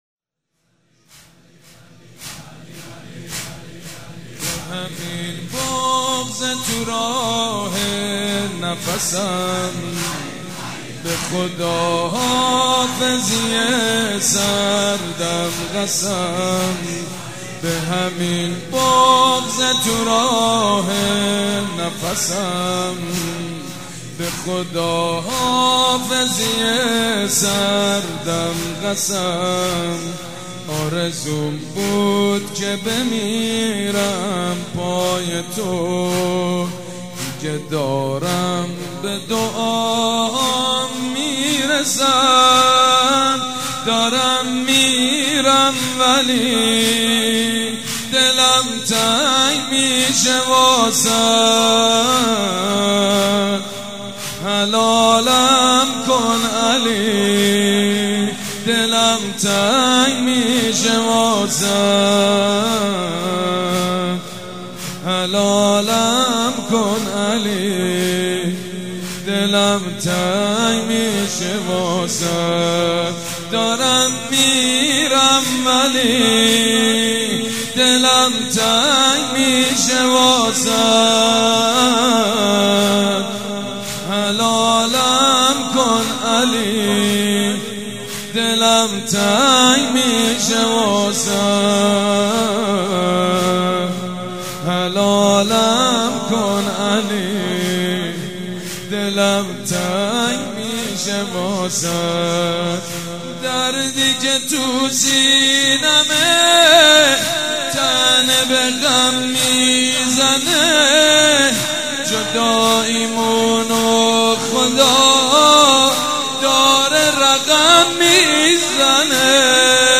شب پنجم فاطميه دوم١٣٩٤ هيئت ريحانة الحسين(س)
مراسم عزاداری شب شهادت حضرت زهرا (س)